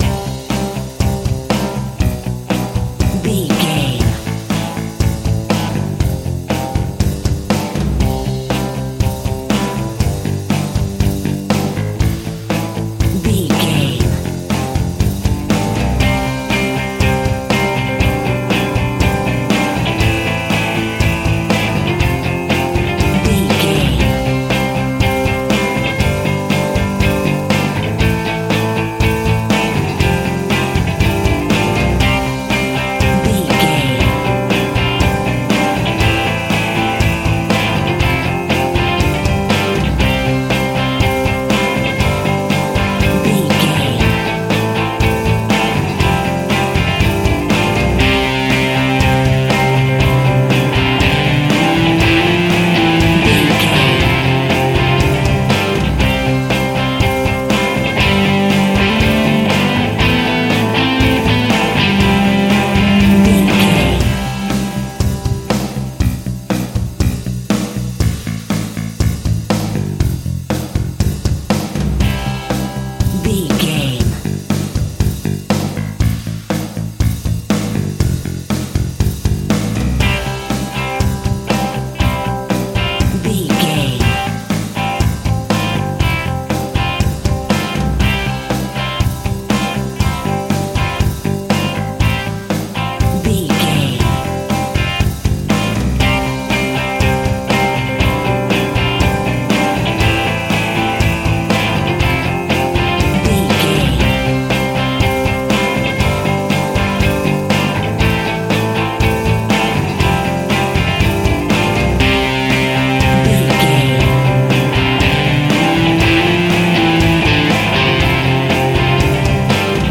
Pop Rock With an Edge.
Fast paced
Ionian/Major
energetic
uplifting
drums
bass guitar
electric guitar
synthesizers